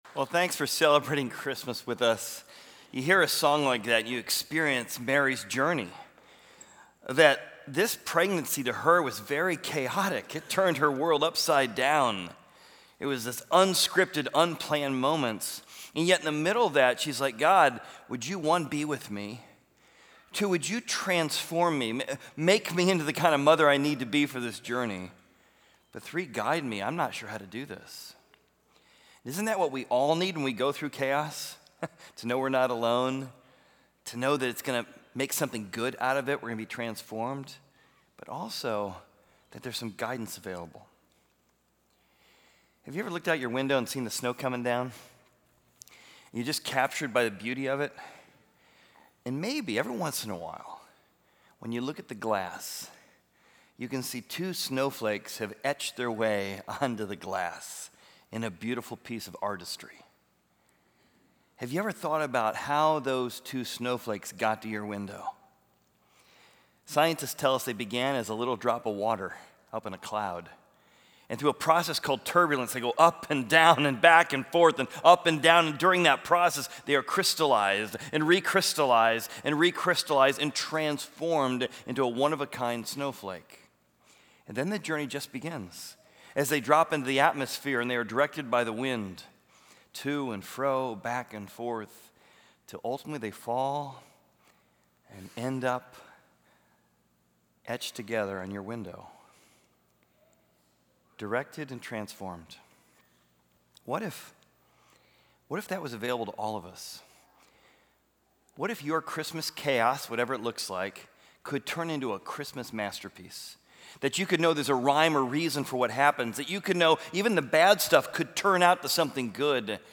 Join Horizon Community Church for a Christmas Candle Lighting Service! The 45-minute service will be a reflective and moving celebration of the birth of Jesus Christ, highlighted by music, carols, and a traditional candle lighting.